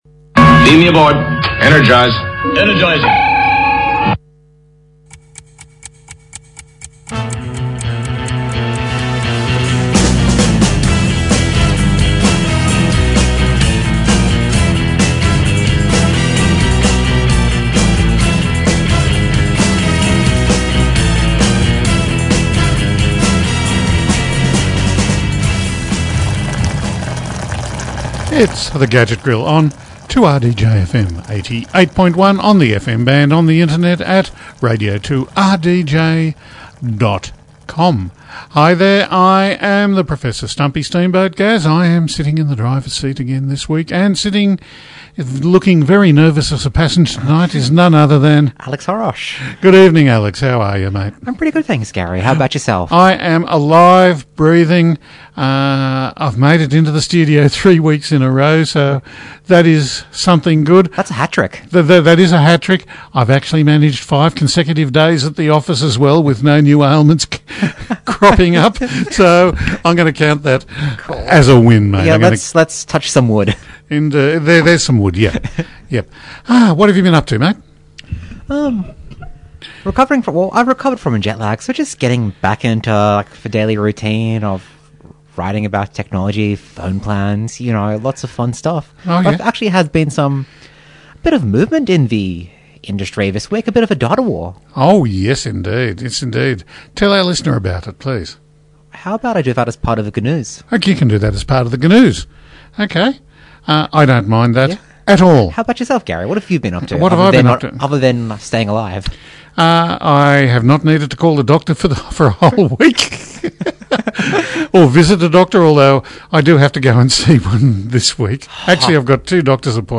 On this week’s show we discuss the latest tech news, and play some great music too.